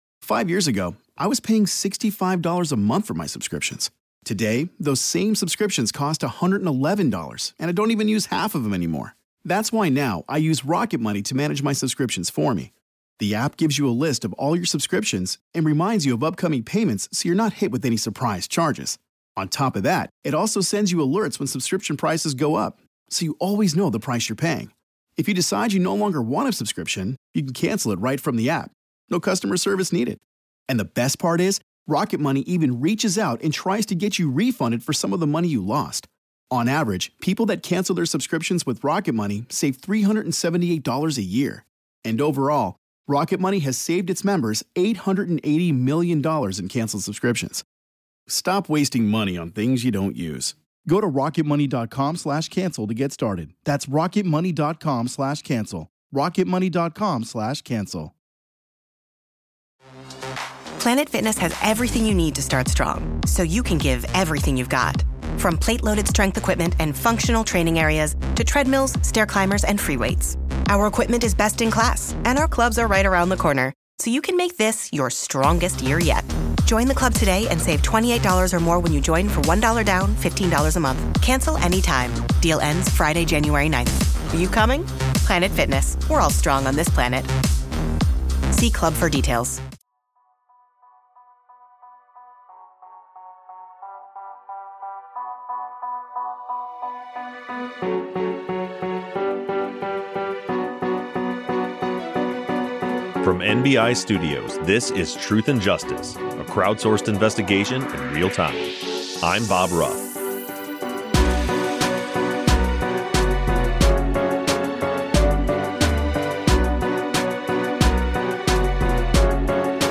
live streams with Patrons